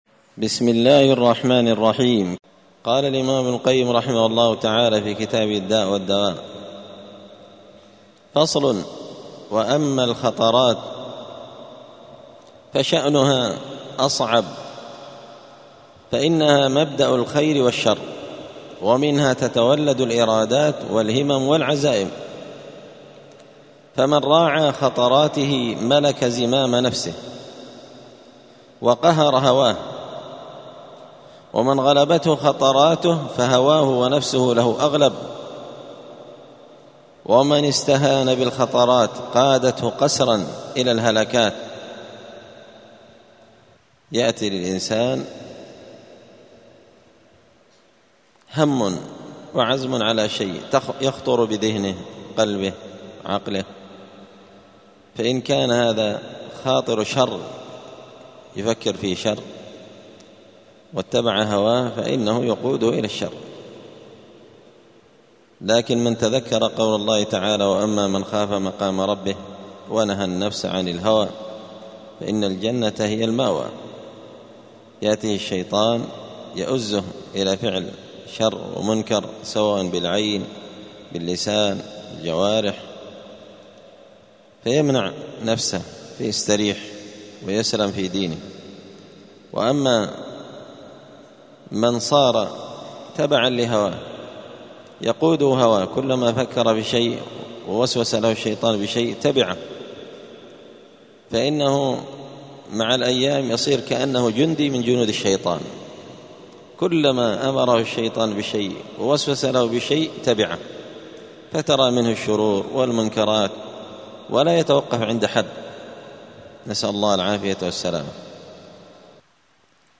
*الدرس الثامن والستون (68) فصل الخطرة*